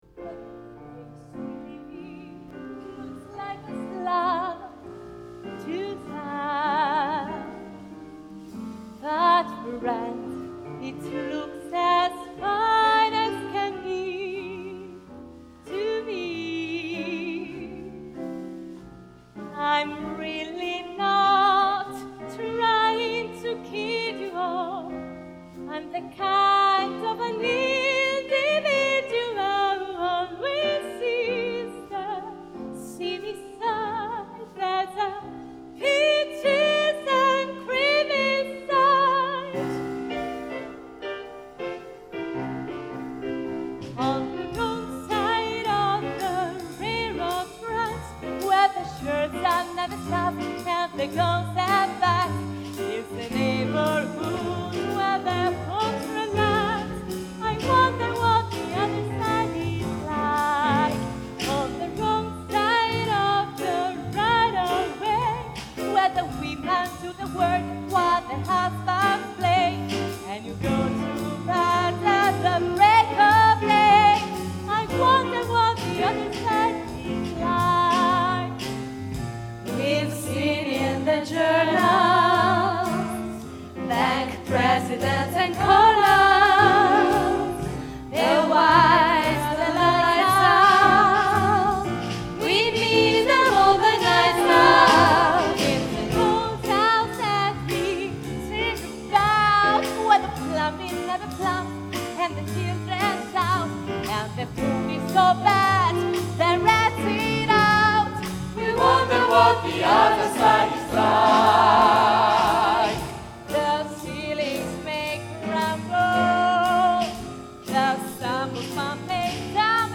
sax contralto
sax tenore
clarinetto
tromba
trombone
chitarra elettrica
pianoforte
basso elettrico
batteria